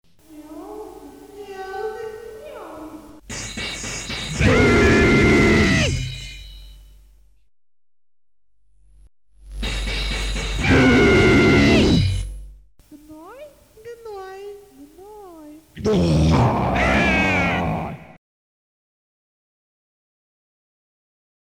Самая короткая песьня в репертуре группы 1991-го года
Heavy Metal